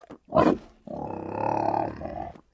Все записи сделаны в естественной среде обитания животного.
Хрюканье свиней